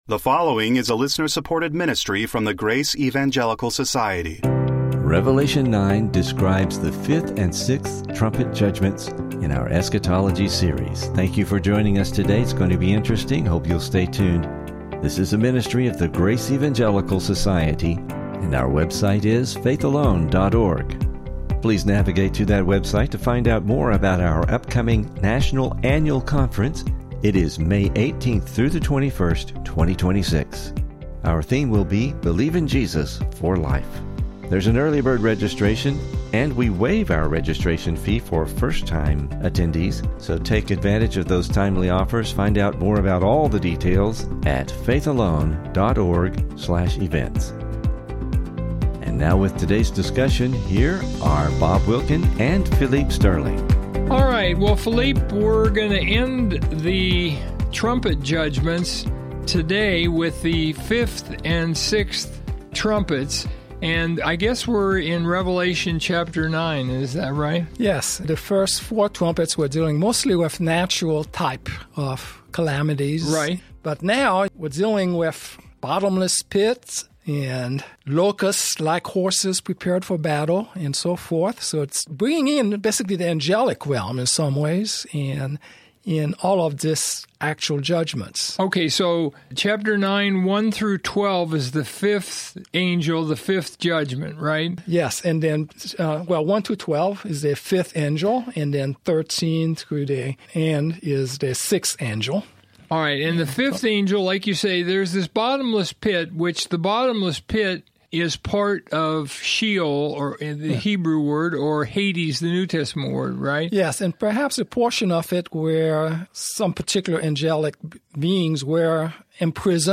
What are the locusts? Are they literal or do they represent fallen angels of the demonic realm? Please listen for a most interesting discussion, and never miss an episode of the Grace in Focus Podcast!